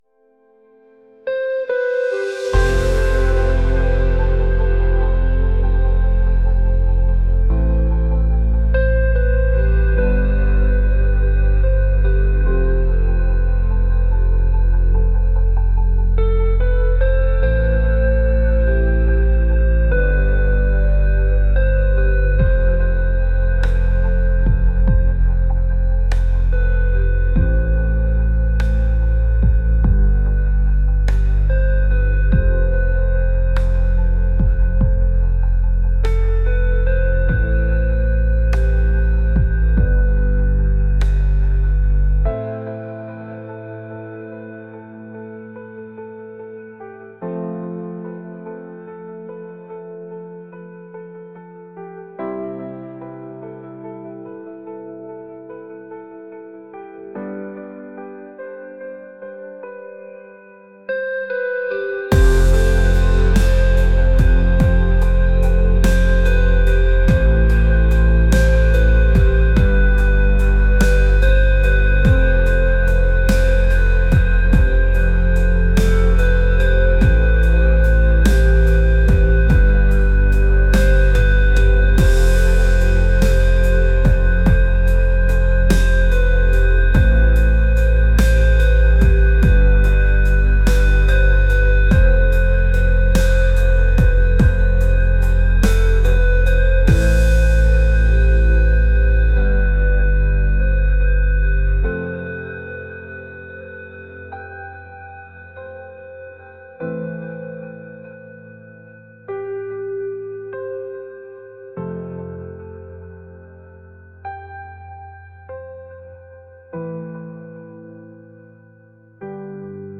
ethereal | atmospheric | pop